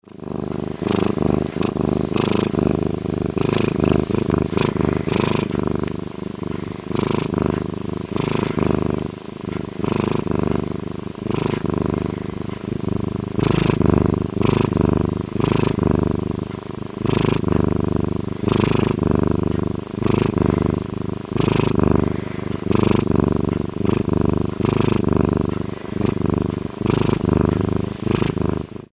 Звуки рыси
Мурлычет